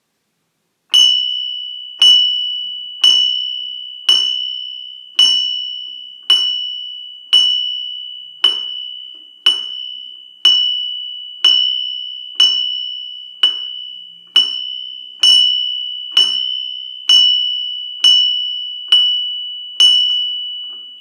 Zvonček nástenný so sovou 19 x 25 cm liatina